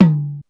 • Tom Drum D Key 04.wav
Royality free tom sample tuned to the D note. Loudest frequency: 252Hz
tom-drum-d-key-04-cO8.wav